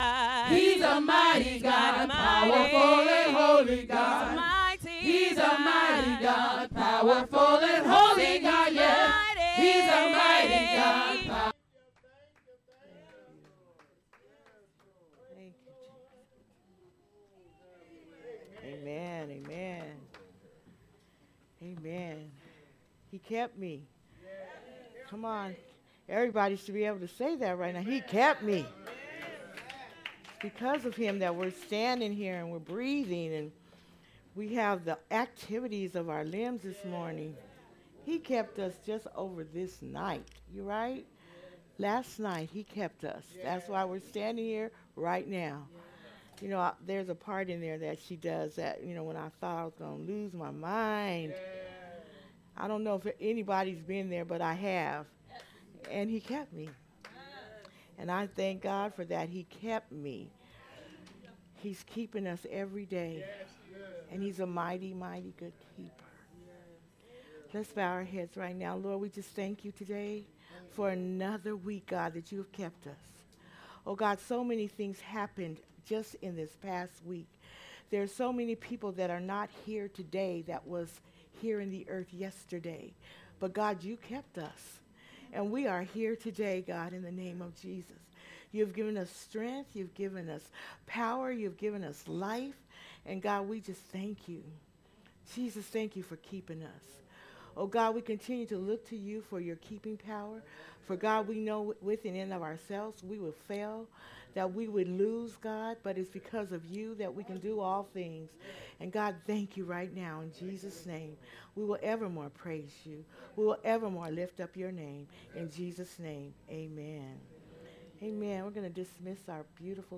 Sermons by Living Faith Christian Center OH